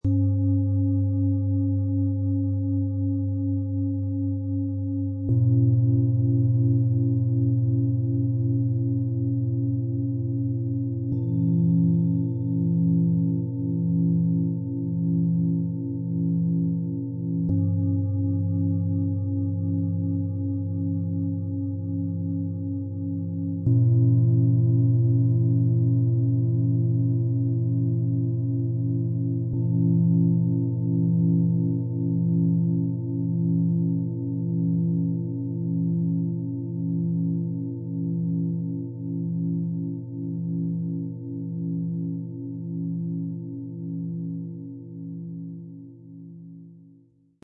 Erden, Zentrieren, Leuchten - Set aus 3 Klangschalen für Klangmassage und Klangmeditationen, Ø 18,4 - 26,4 cm, 4,08 kg
Drei größere Klangschalen vereinen tiefe, körperliche Resonanz mit einer einzigartigen, matten Optik.
Die größte Schale schenkt ein mächtiges, sonores Fundament, das lange nachschwingt.
Diese große Universalschale klingt warm, voll und harmonisch.
Die kleinste Schale dieses Sets klingt hell, weich und freundlich.
Mit dem Sound-Player - Jetzt reinhören können Sie den Originalklang genau dieser drei Schalen live erleben - satt, klar und mit spürbarer Vibration.
Der mitgelieferte Klöppel bringt die drei Schalen weich und klangvoll zum Schwingen.
Klangcharakter: tief, warm, raumfüllend
Klangcharakter: rund, weich, stabilisierend